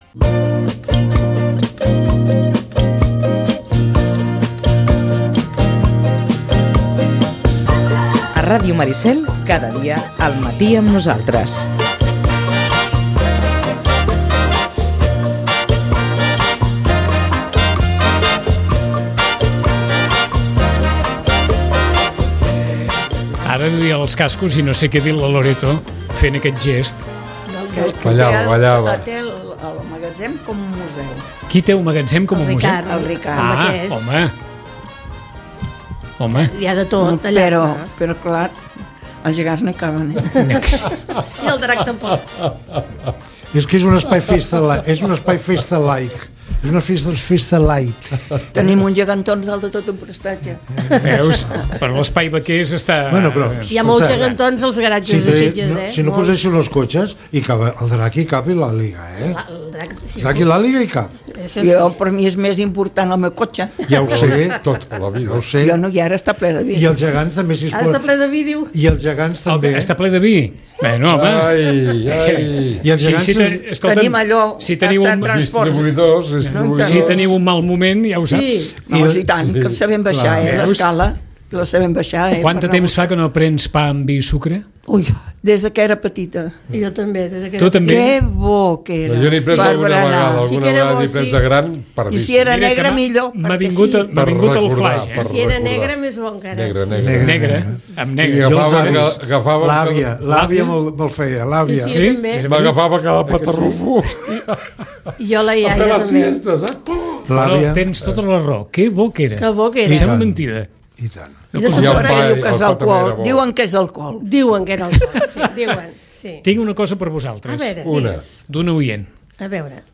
Tertúlia
Ràdio Maricel. Emissora municipal de Sitges. 107.8FM. Escolta Sitges.